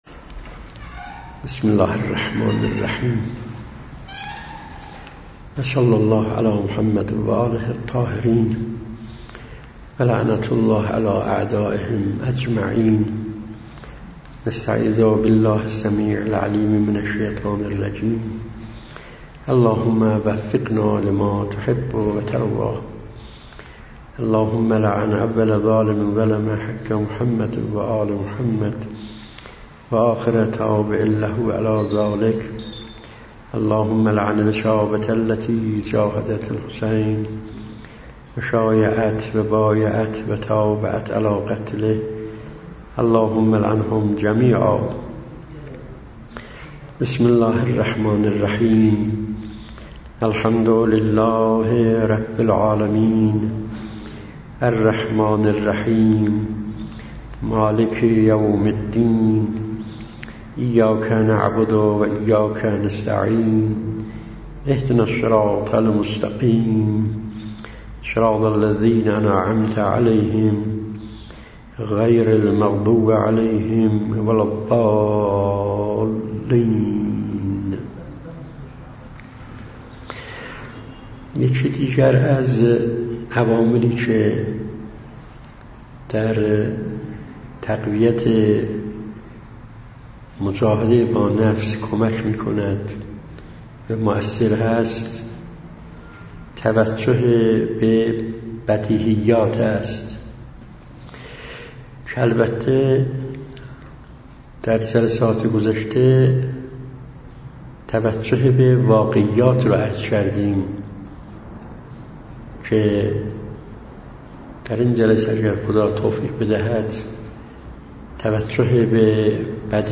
سخنرانی های سال 95